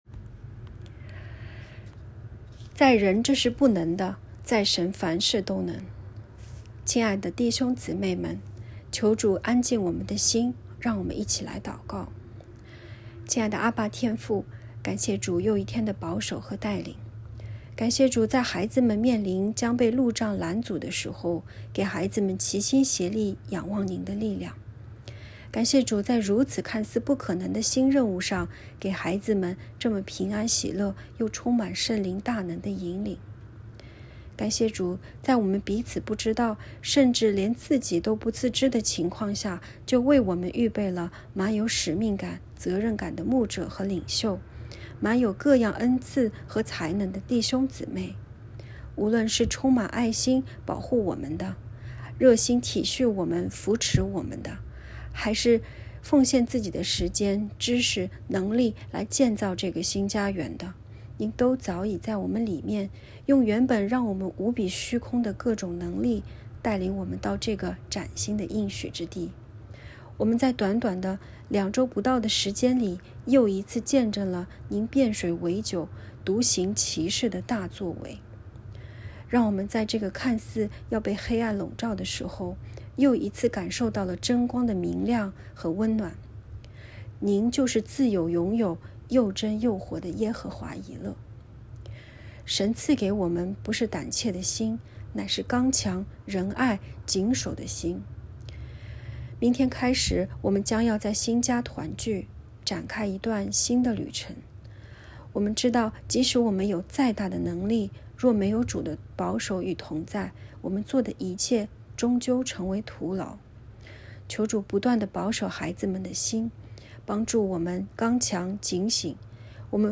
✨晚祷时间✨2022年1月16日（周日）